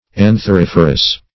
Search Result for " antheriferous" : The Collaborative International Dictionary of English v.0.48: Antheriferous \An`ther*if"er*ous\, a. [Anther + -ferous.]
antheriferous.mp3